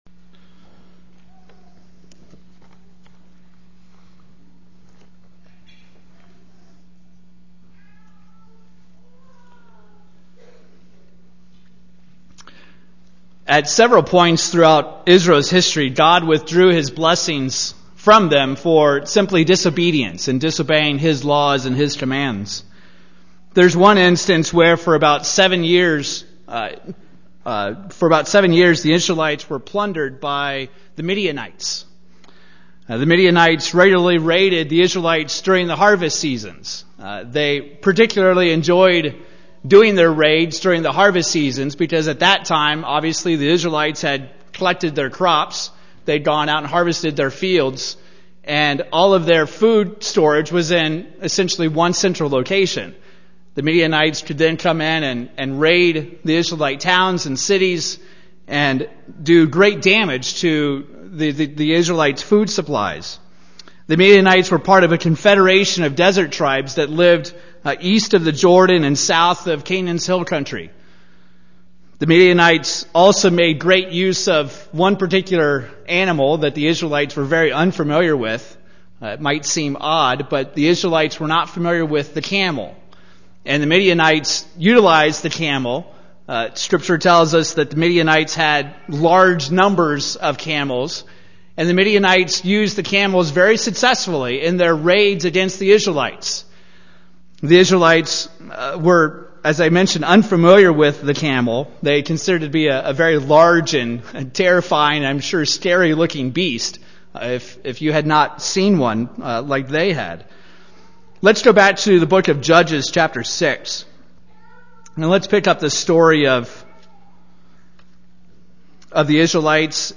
These are the notes taken live during services as captioning for the deaf and hard of hearing.
UCG Sermon Notes These are the notes taken live during services as captioning for the deaf and hard of hearing.